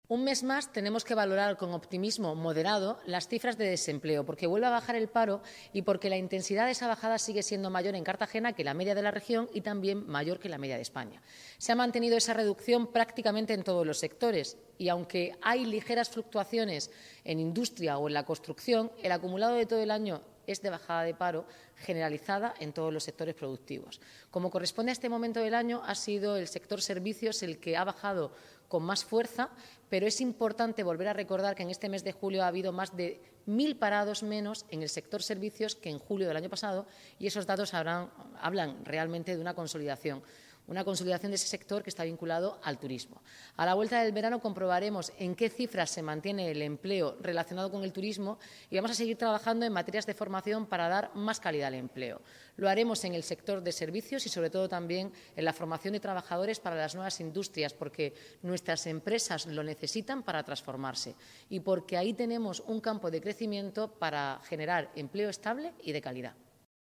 Enlace a Declaraciones Noelia Arroyo sobre el empleo en Cartagena.